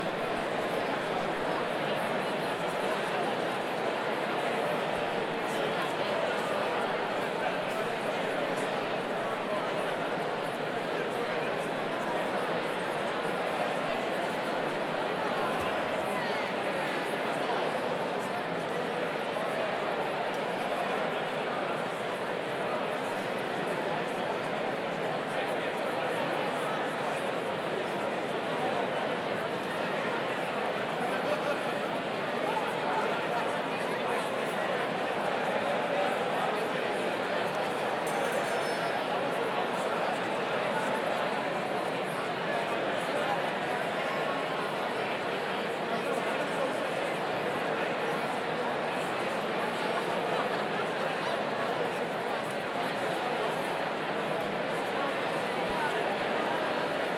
Large_crowd_medium_distance_stereo
ambience auditorium crowd Crowd field-recording hall indoor indoors sound effect free sound royalty free Sound Effects